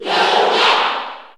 Crowd cheers (SSBU) You cannot overwrite this file.
Dr._Mario_Cheer_English_SSB4_SSBU.ogg